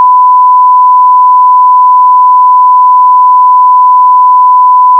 Part6-1kHz.wav